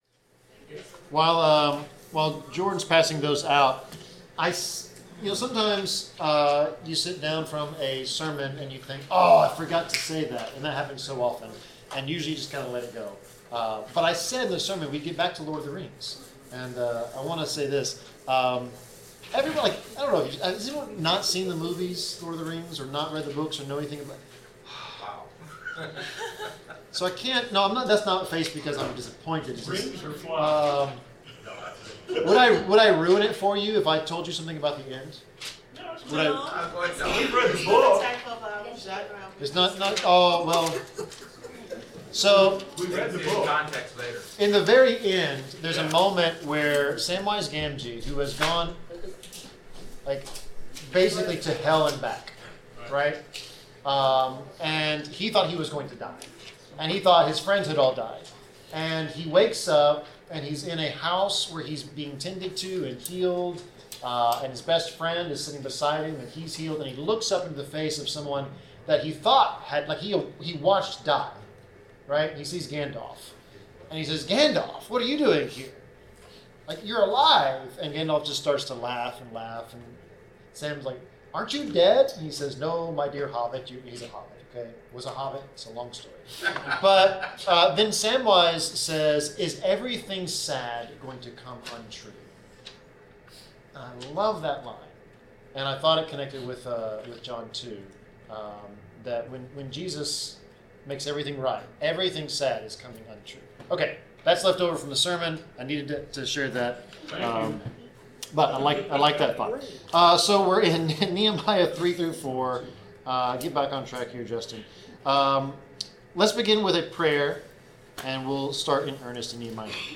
Bible class: Nehemiah 3-4
Service Type: Bible Class